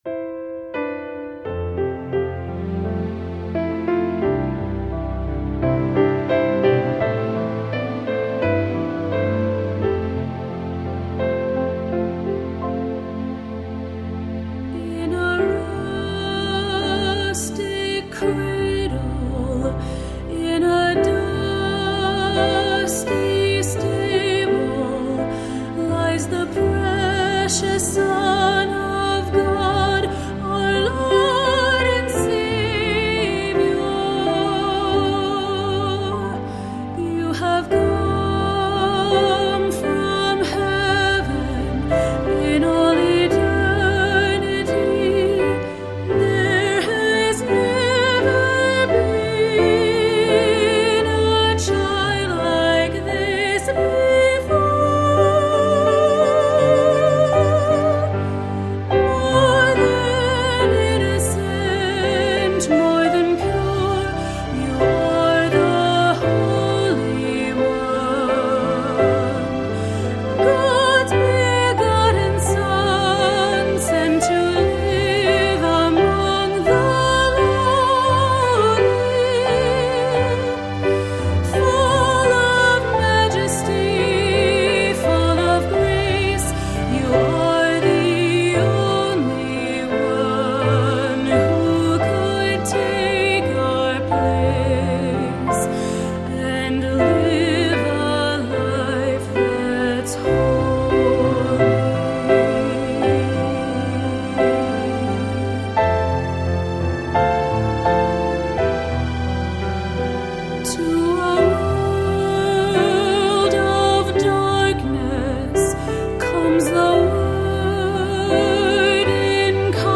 Voicing: Medium Voice